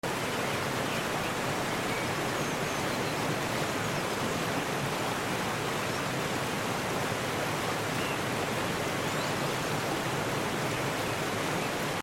Relaxing on the sun kissed forest sound effects free download
From this peaceful spot along the creek, the sound of rushing water leads the eye to a stunning waterfall framed by moss-covered rocks and evergreens.